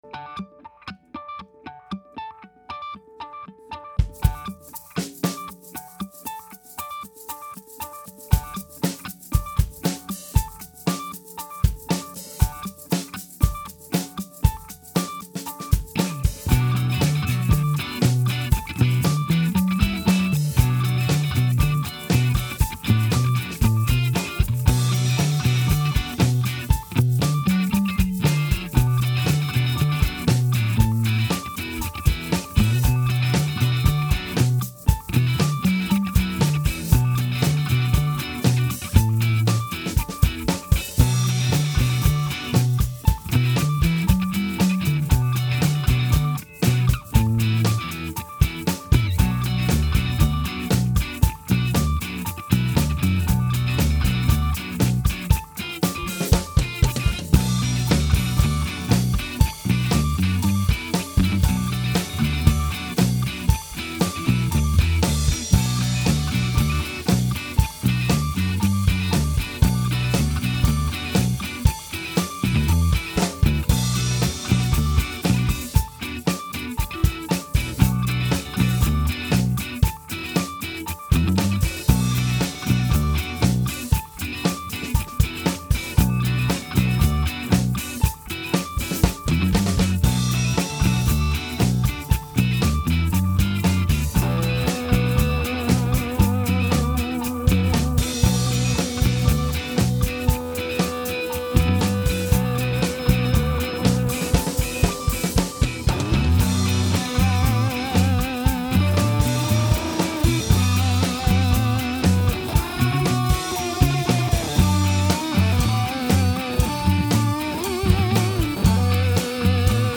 drums
We overdubbed the solo work and bouncy rhythm.